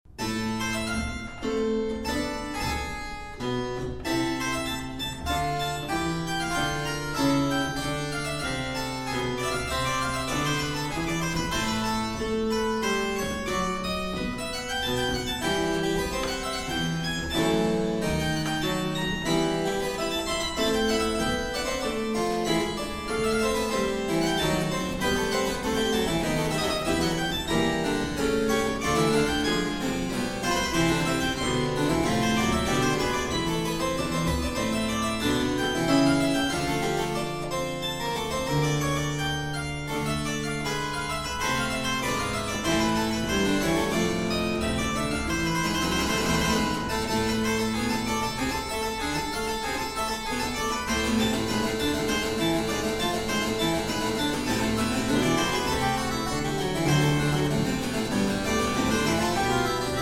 Pedal Harpsichord, Organ